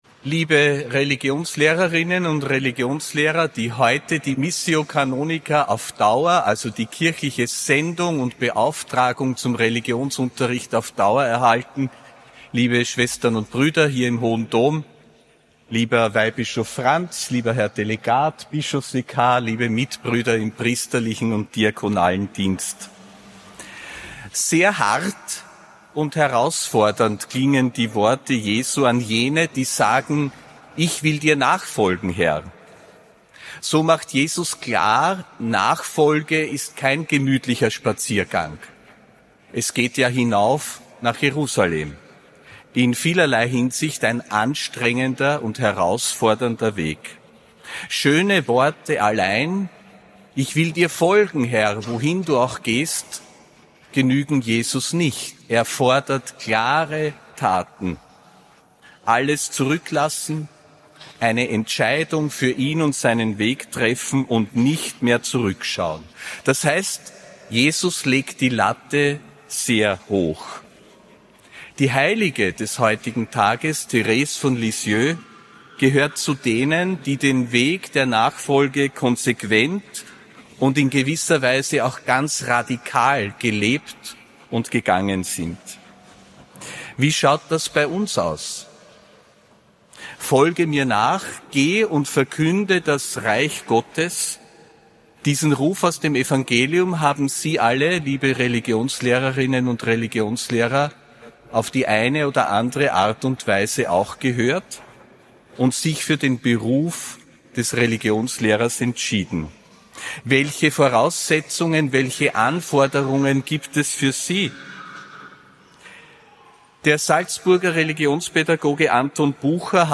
Predigt von Josef Grünwidl zur Verleihung der missio canonica auf Dauer an die Religionslehrerinnen und Religionslehrer